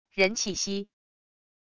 人气息wav音频